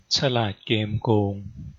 1. ^ The Thai title (pronounced [tɕʰa.làːt.kēːm.kōːŋ]
Th-ฉลาดเกมส์โกง.ogg.mp3